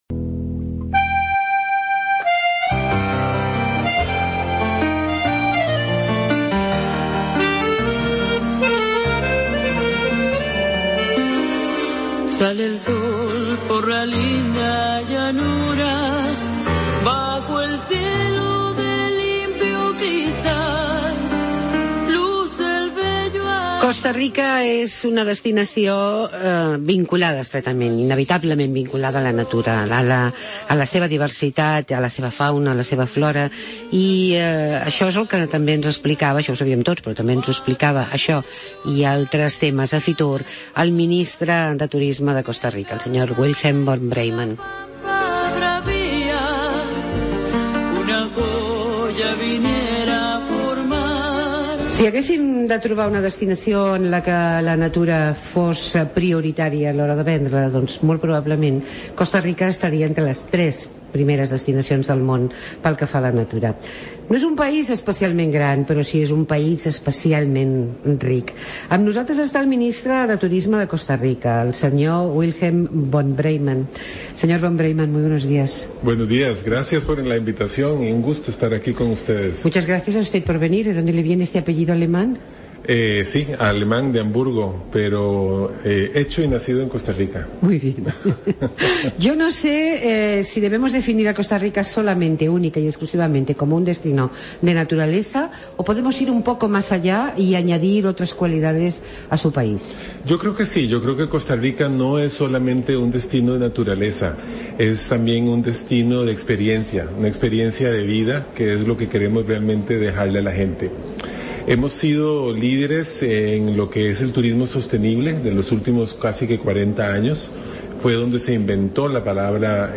Estuvo con nosotros el ministro de Turismo de Costa Rica, Wilhem Von Breymann.